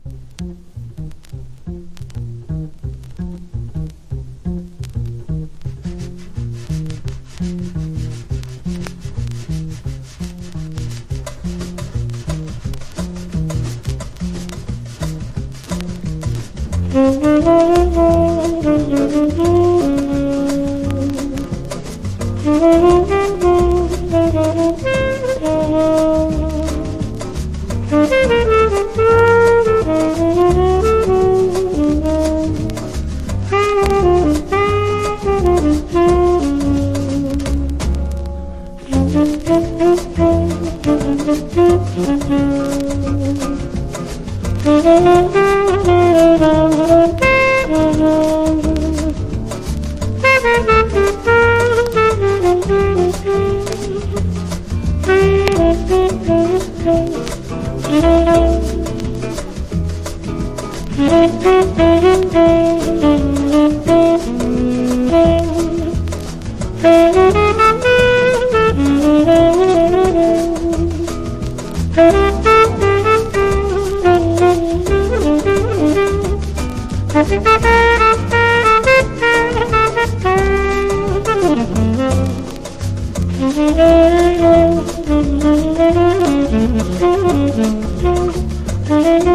# LATIN